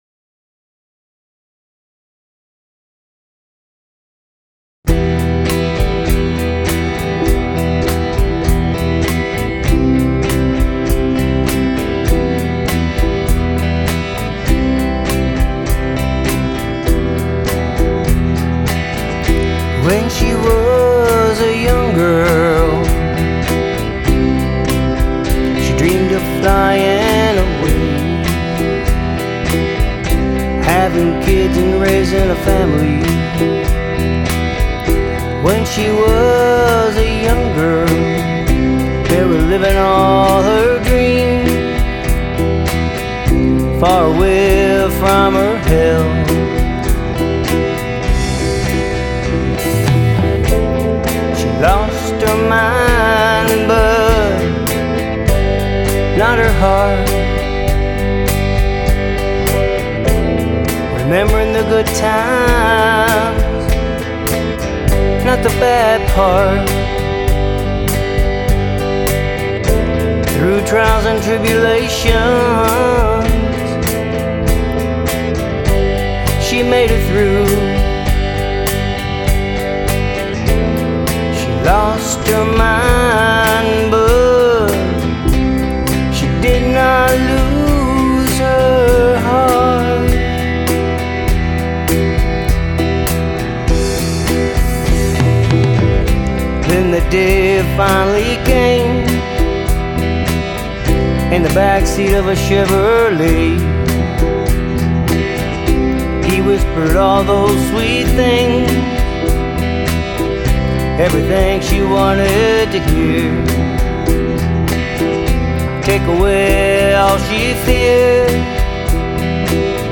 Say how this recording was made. This one is still rough, but I like the direction. I wrote this as I am watching my mom go through life. If you have tips, please share as it will need to be re-tracked.